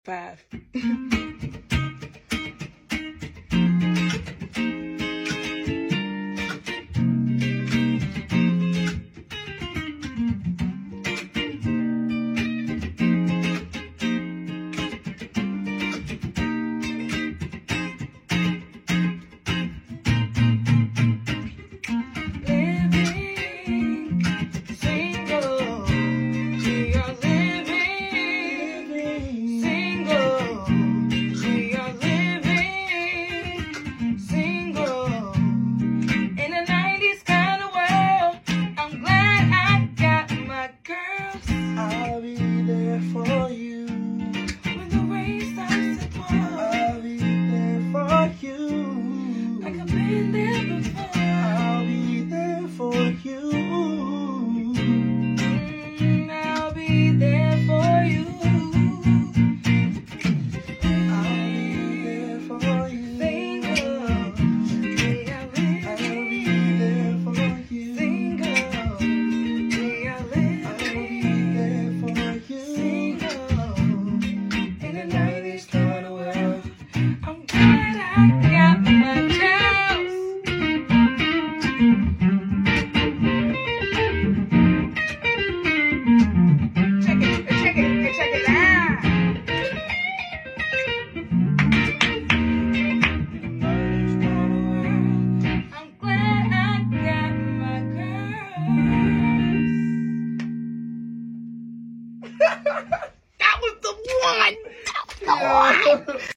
Bringing two artists and two classic theme songs together!